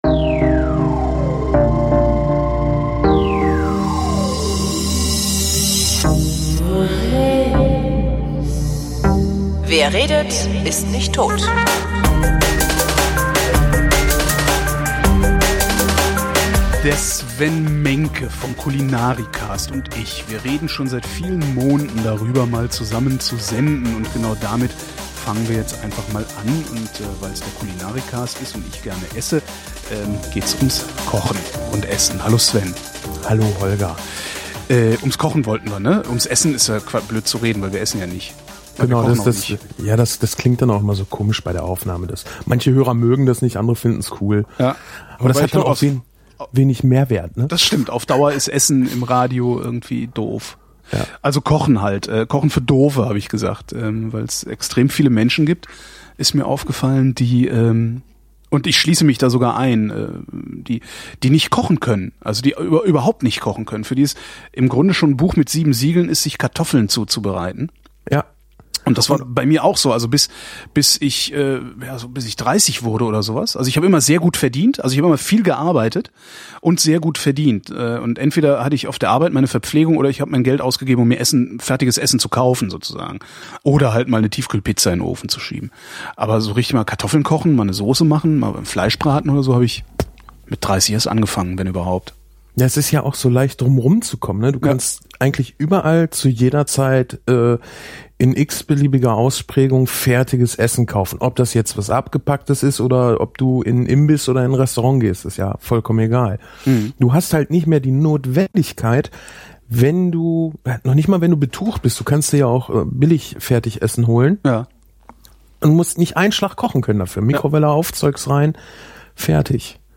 Wir haben uns gedacht, es könnte hilfreich sein, auf Anfängerniveau übers Kochen zu reden und das hier ist unsere erste Folge. Bitte denkt daran, dass es immer einige Sendungen dauert, bis zwei Moderatoren sich aufeinander eingespielt haben.